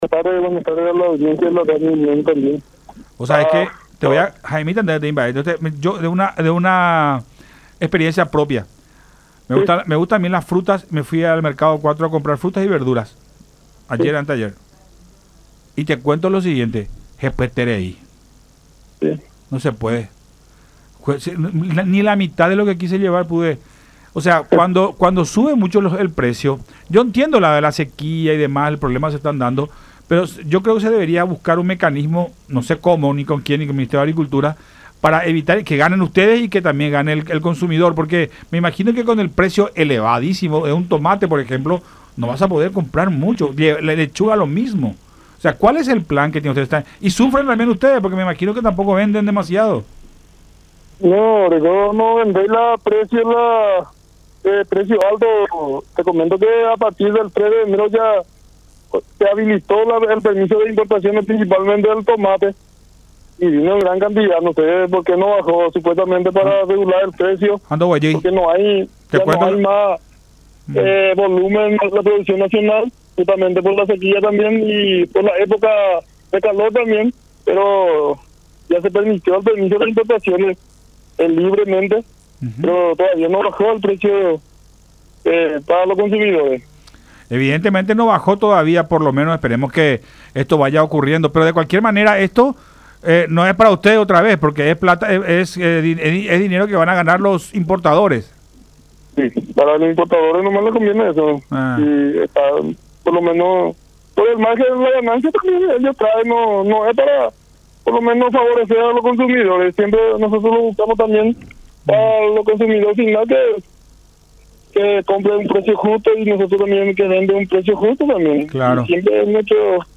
en conversación con Buenas Tardes La Unión.